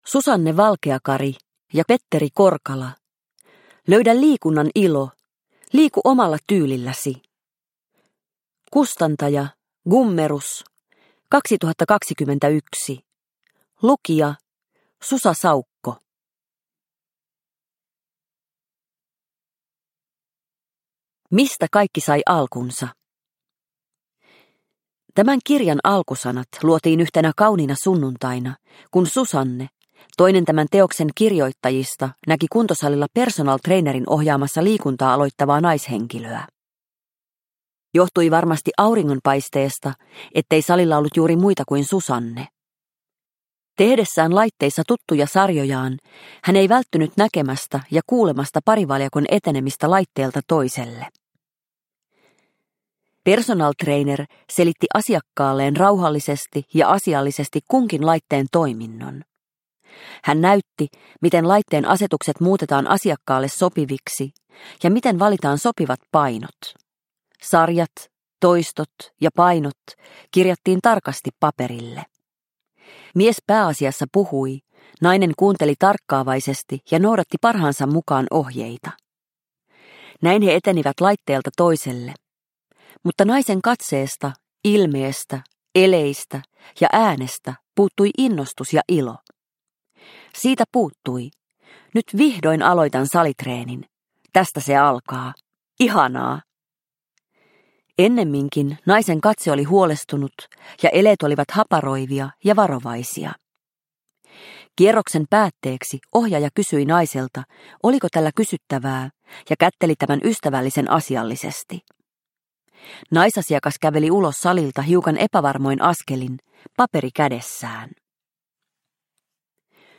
Löydä liikunnan ilo – Ljudbok – Laddas ner